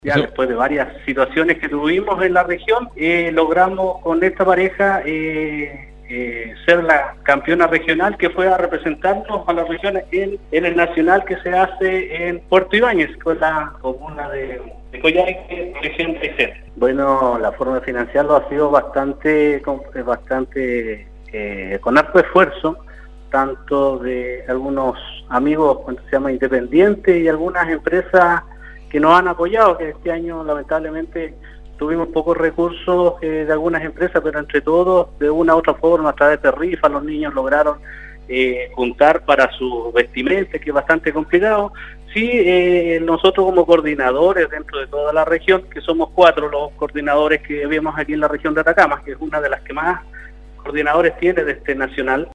ENTREVISTA-2.mp3